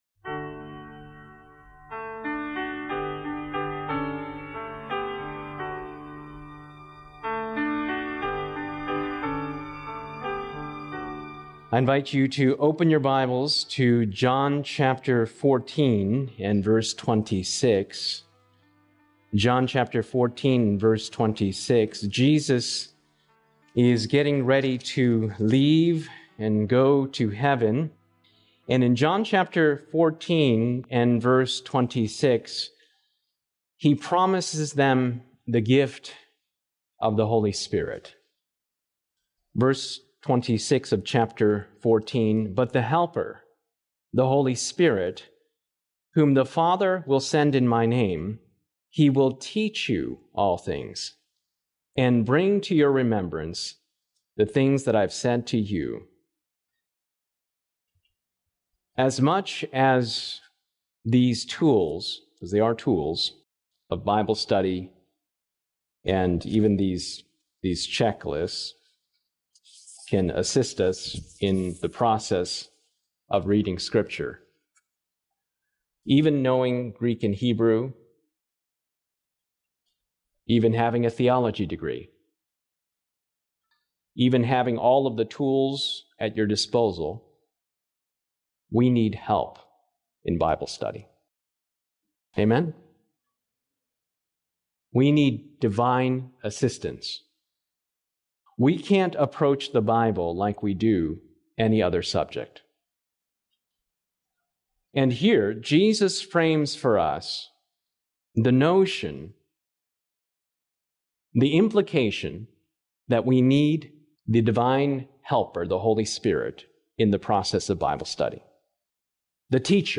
This sermon reveals why true understanding of Scripture requires more than intellect—it demands the guidance of the Holy Spirit through prayerful dependence. Discover the urgent call for revival, the power of persistent prayer, and the transformative presence of Christ within every Spirit-filled believer.